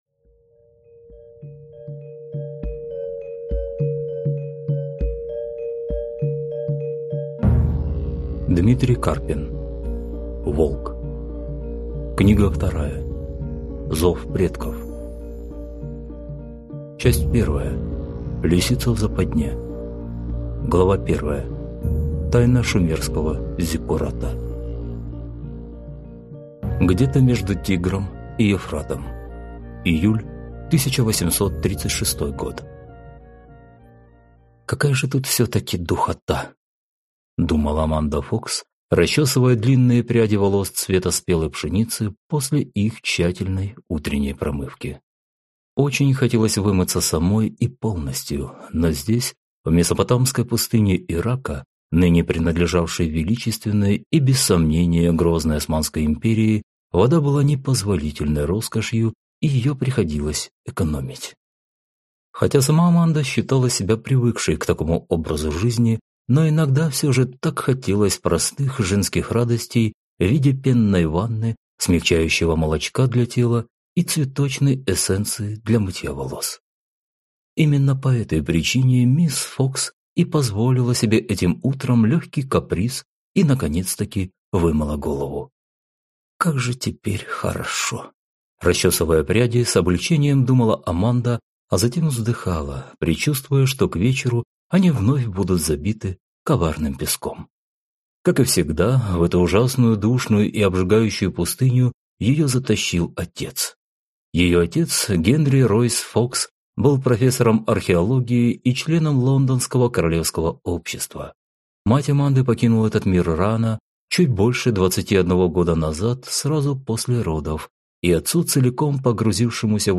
Аудиокнига Волк. Зов предков | Библиотека аудиокниг